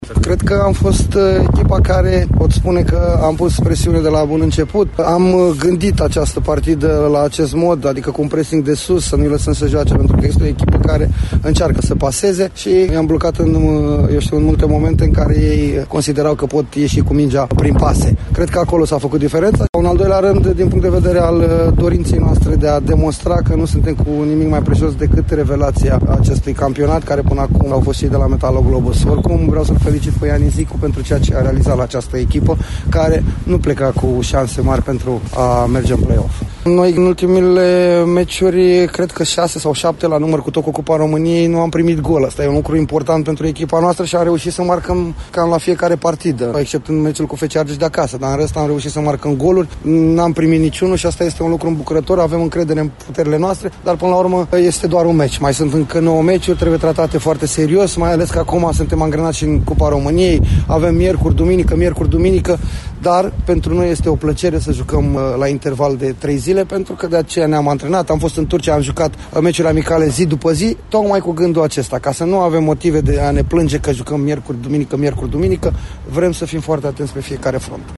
Despre perioada aglomerată din „agenda” cărășenilor a vorbit tot antrenorul lor, Flavius Stoican: